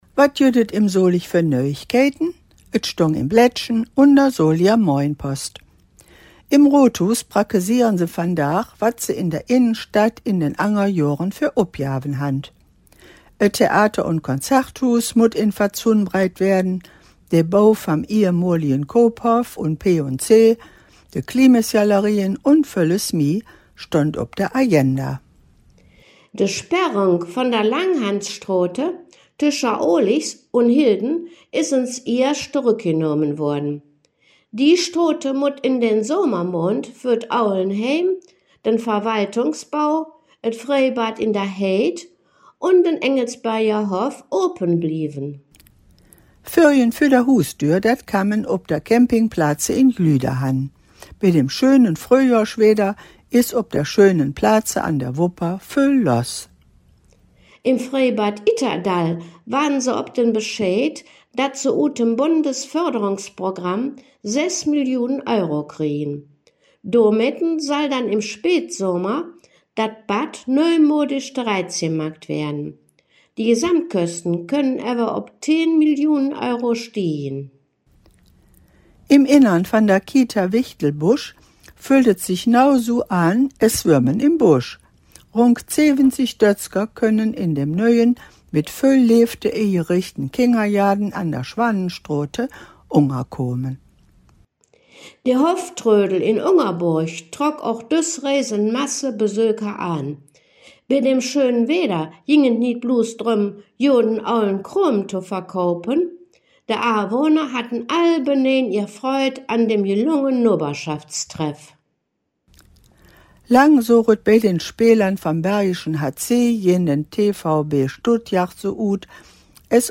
Folge 280 der Nachrichten in Solinger Platt von den Hangkgeschmedden: Themen u.a.: Wauler Stadion,Iissporthall, Freïbad Itterdall